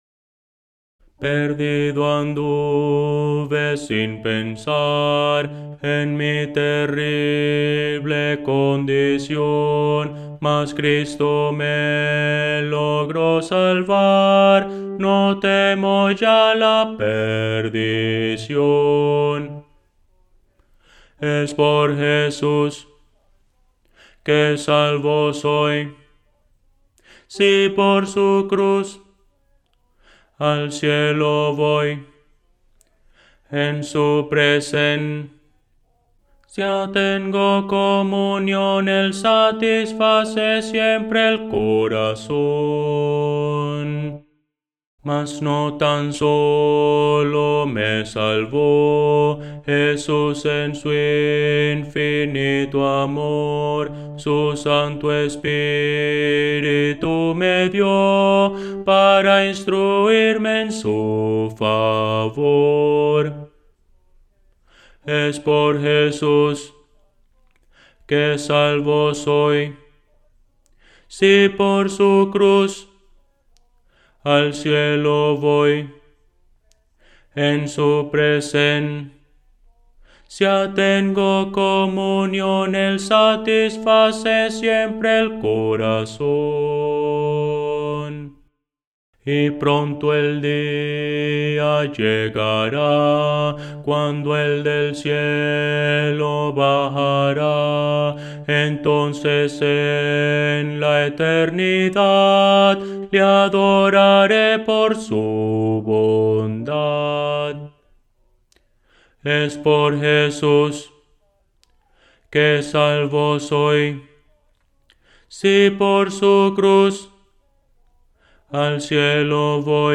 A capela – 4 Voces
Voces para coro
Bajo – Descargar